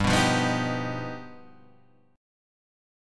G+7 chord {3 2 3 4 4 3} chord